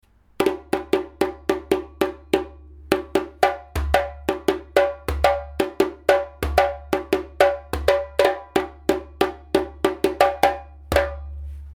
レンケサウンド（MALI Lenke 34cm 2024）
その双方の良いところを持ち合わせているのがママディが愛したということで世界的に人気なレンケ材なのです。
カラッと抜ける太いレンケサウンドは叩くことでワクワクを高めて日々の暮らしを豊かなものにしてくれるでしょう。